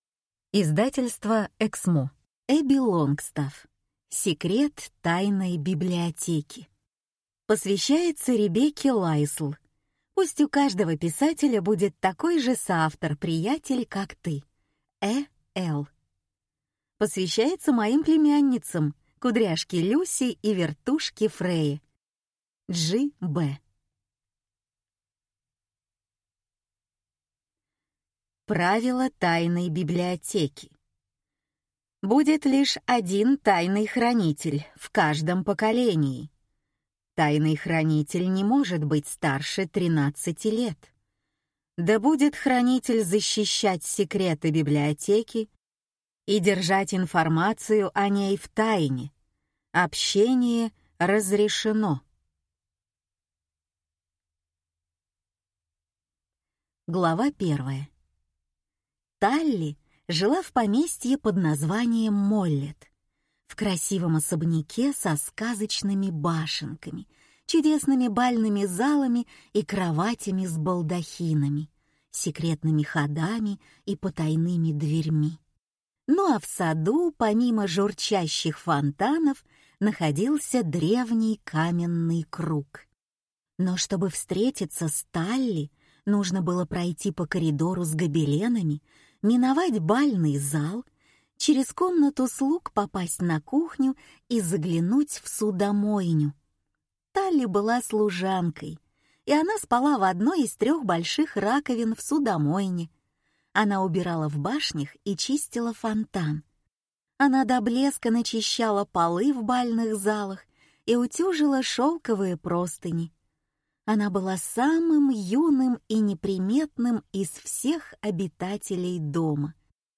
Аудиокнига Секрет Тайной библиотеки | Библиотека аудиокниг